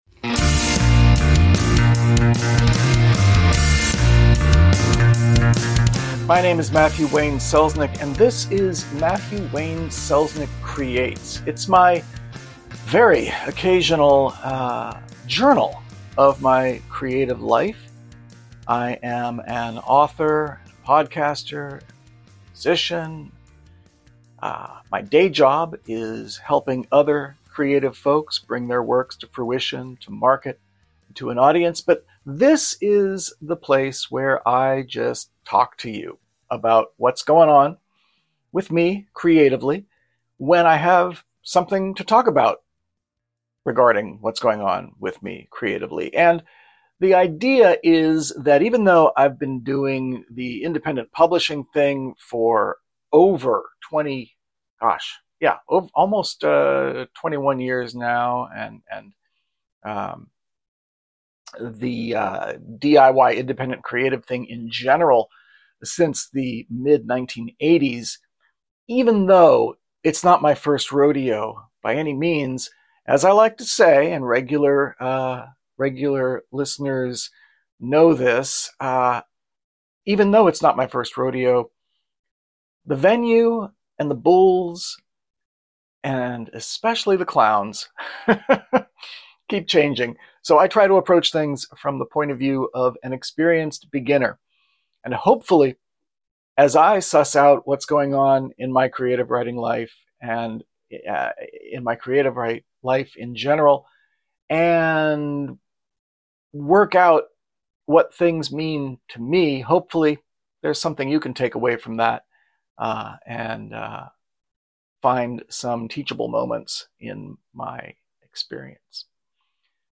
This episode was recorded using my Pixel 9a and Dual Pros Mini Mic Pro .